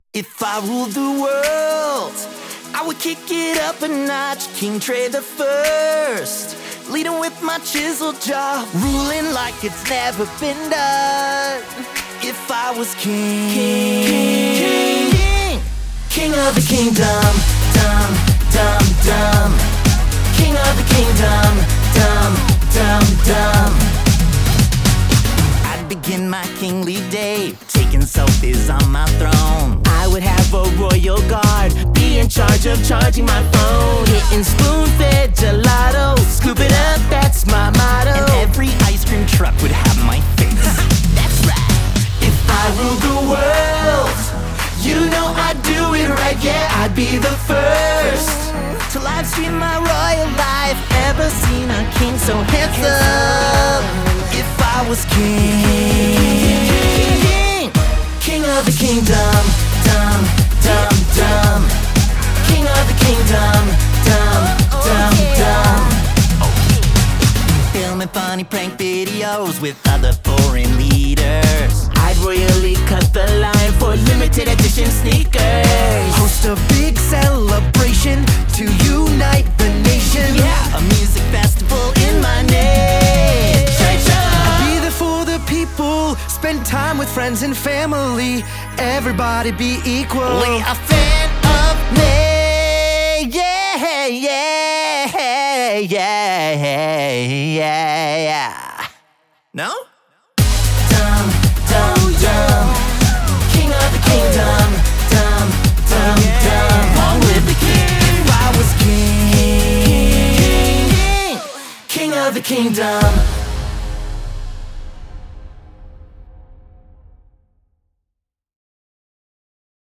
Жанр: Pop music
Genre - Pop, Aqua-core